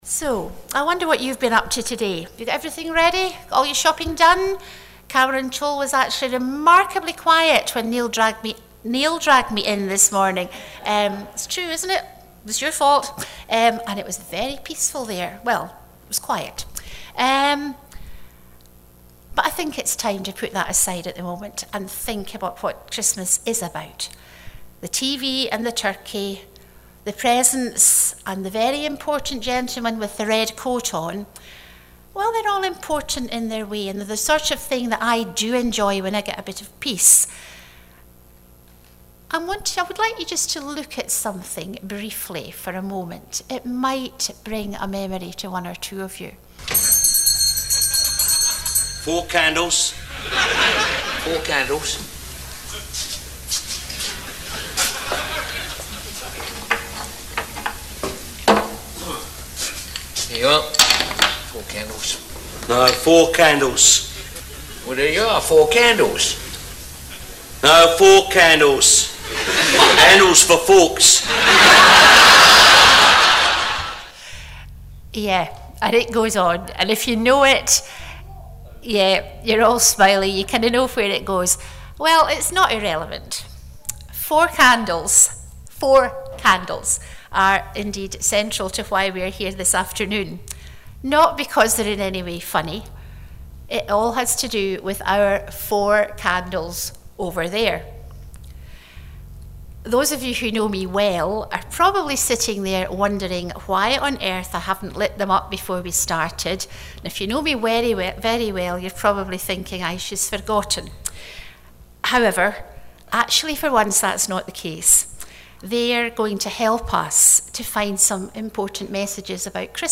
Christmas Eve Afternoon Family Service
four candles’, as presented by the Two Ronnie’s famous comedy sketch.